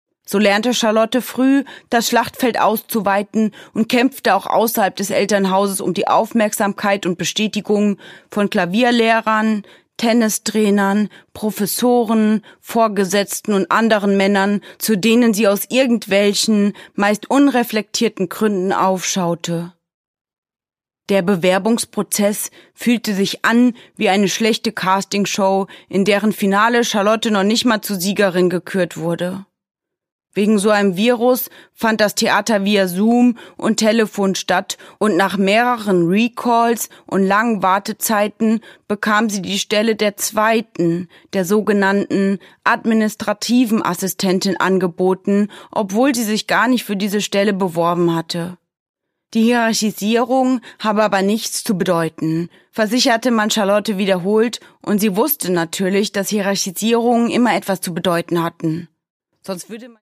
Caroline Wahl: Die Assistentin (Ungekürzte Lesung)
Produkttyp: Hörbuch-Download
Gelesen von: Caroline Wahl